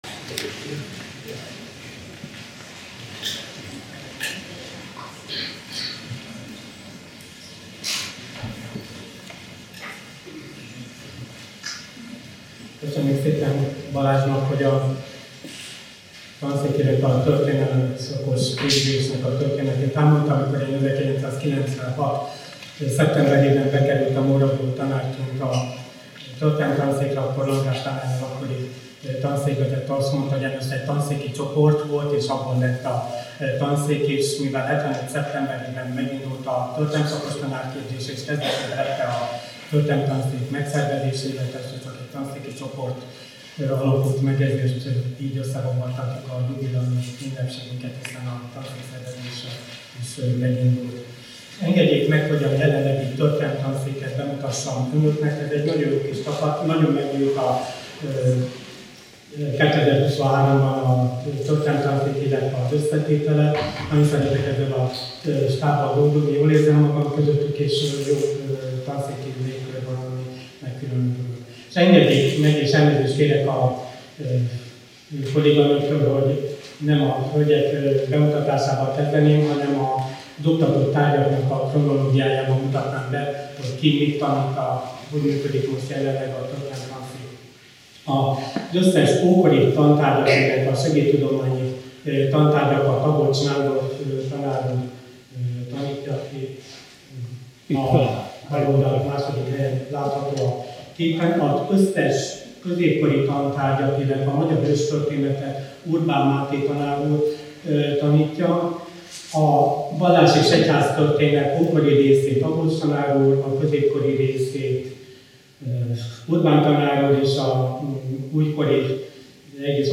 Rendezvény a szombathelyi történelem szakos tanárképzés indulásának és a Történelem Tanszék alapításának 50 éves jubileuma alkalmából.
Előadások, konferenciák